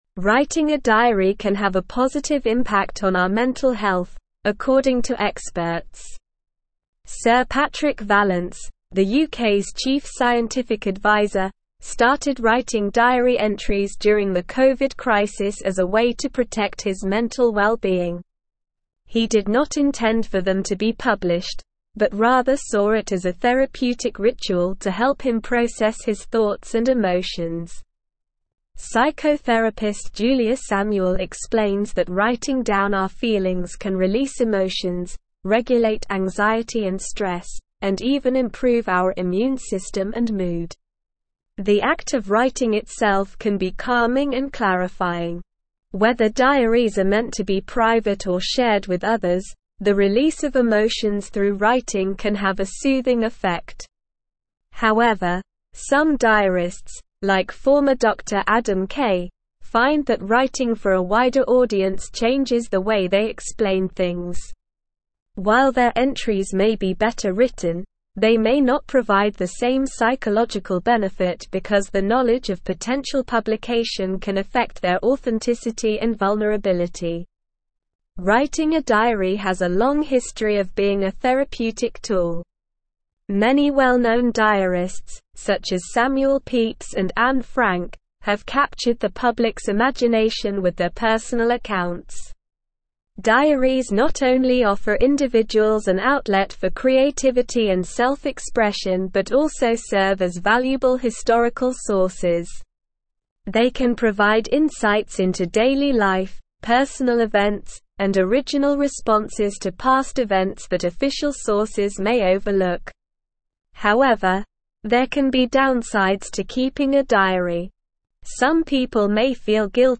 Slow
English-Newsroom-Advanced-SLOW-Reading-The-Therapeutic-Benefits-of-Writing-a-Diary.mp3